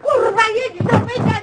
ow2.wav